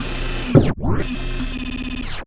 program_end.wav